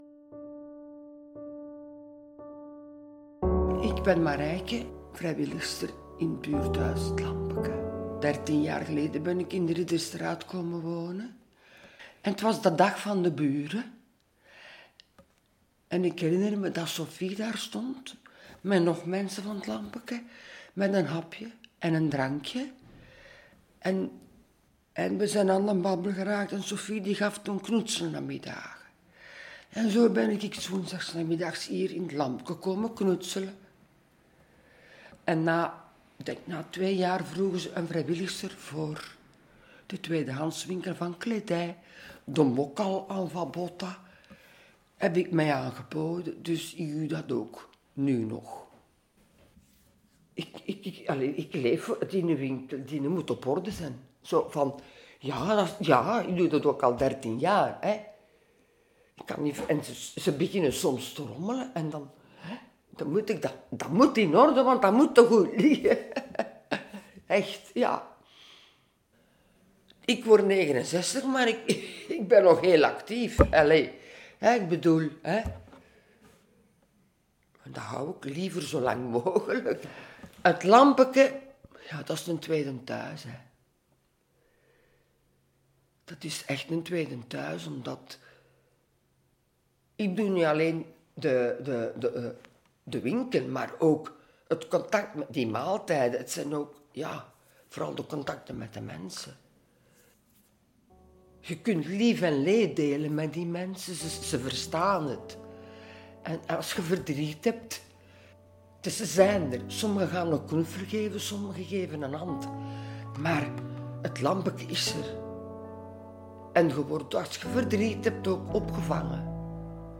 Elk van hen nam ons ook mee naar een plek in de buurt waar zij zich goed voelen. Daar maakten we telkens een audioverhaal.